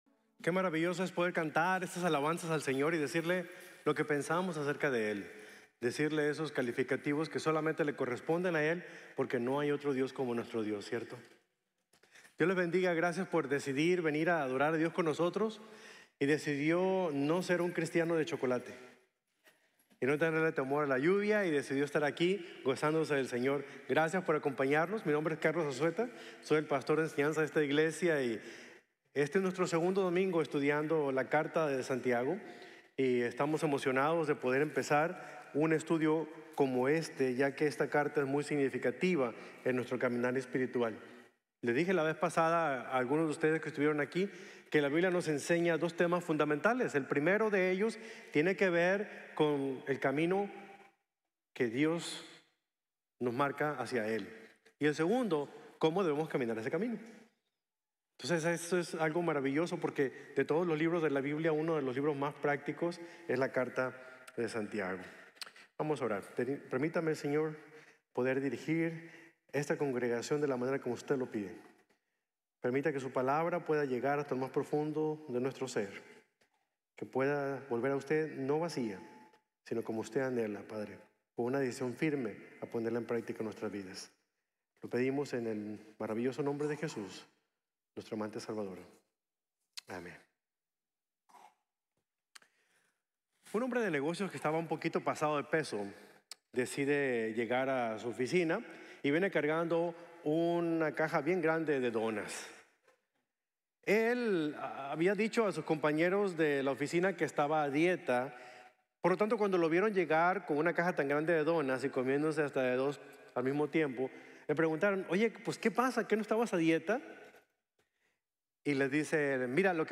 Cómo Superar la Tentación | Sermón | Grace Bible Church